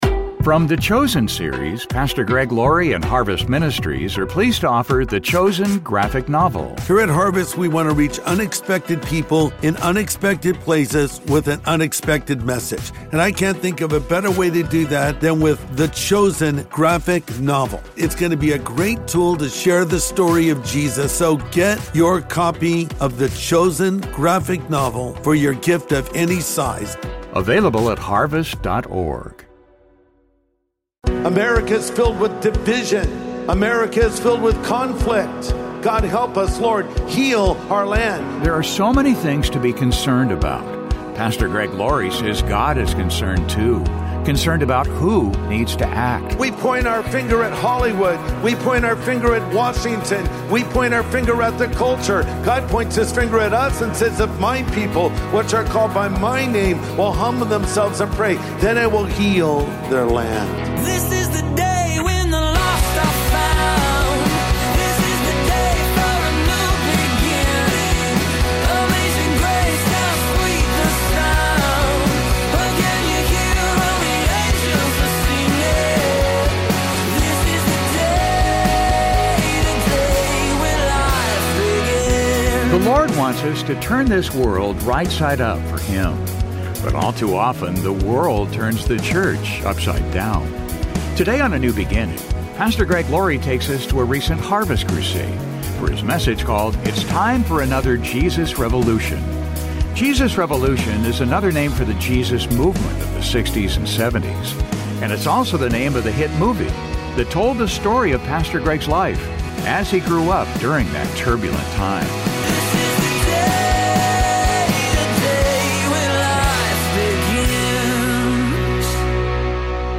takes us to a recent Harvest Crusade for his message